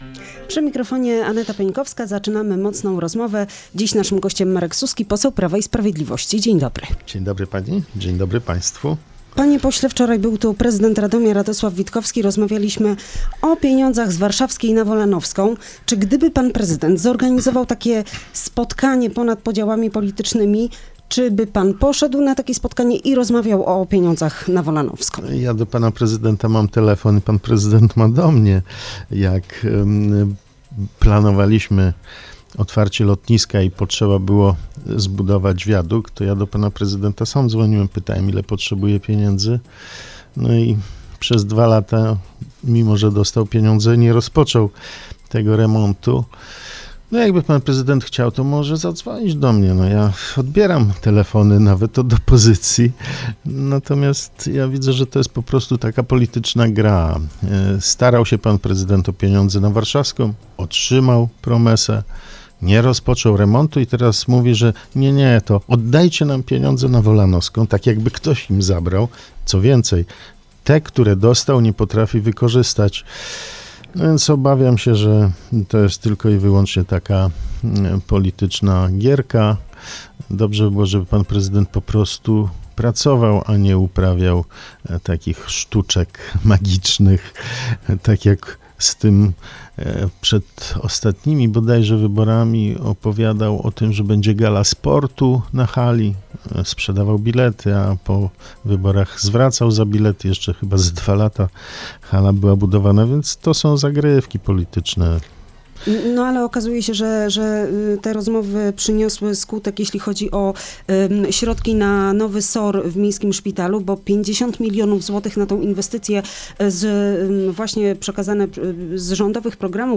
Rozmowa do dosłuchania poniżej: